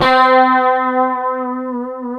C#4 HSTRT VB.wav